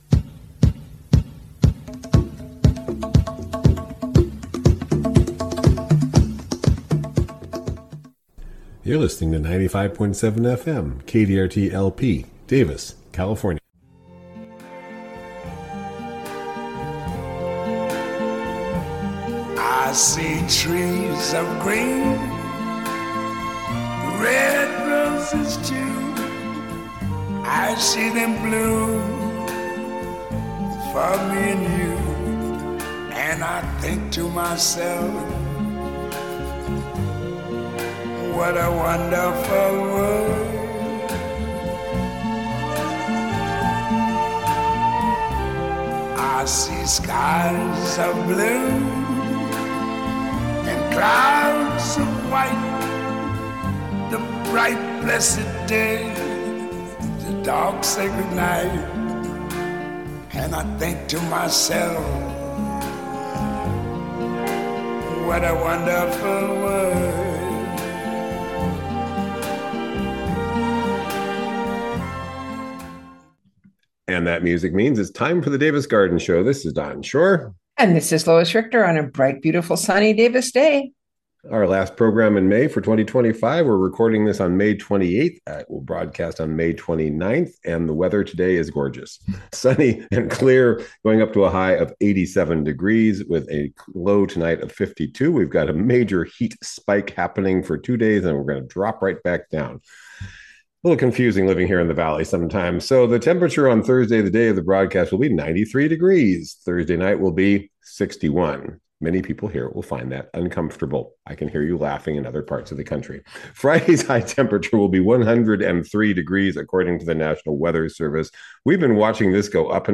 Spring gardening conversations